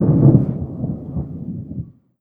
tenkoku_thunder_distant03.wav